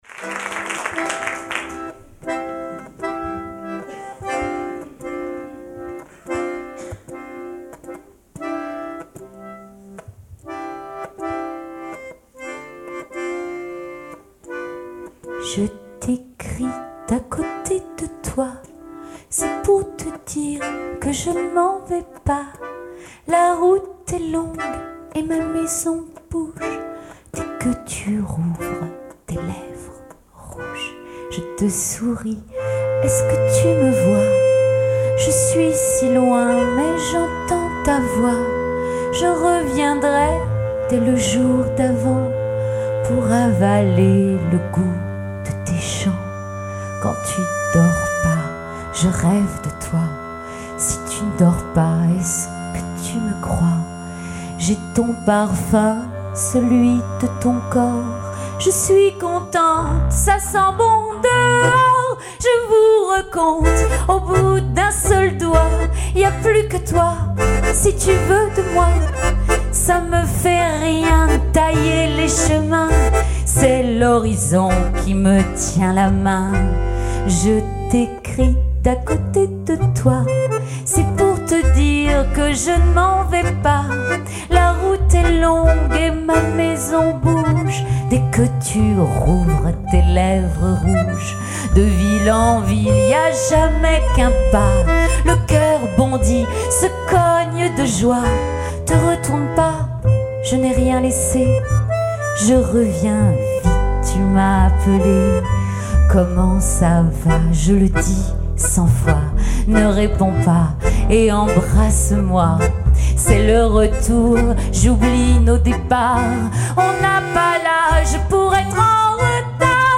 LES CHANSONS TANGOS